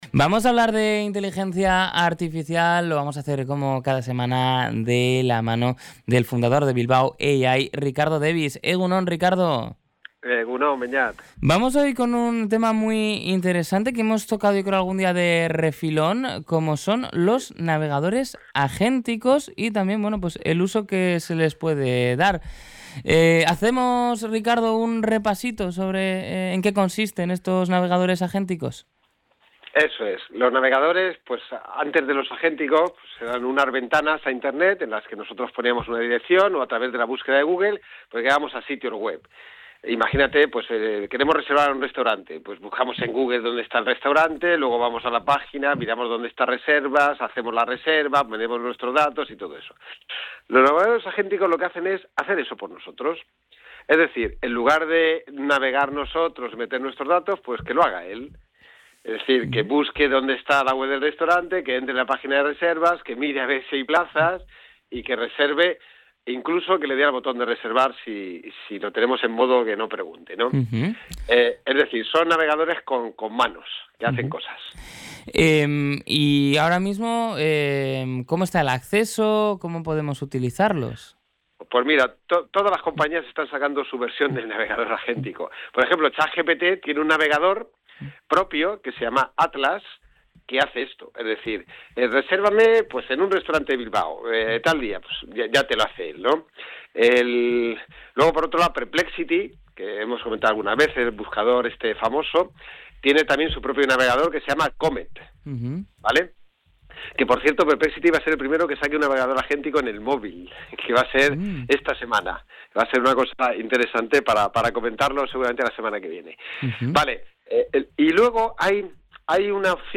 En la última entrega de la sección «IA para andar por casa» en el programa EgunOn Magazine de Radio Popular-Herri Irratia, se ha analizado cómo la relación con internet está sufriendo un cambio de paradigma.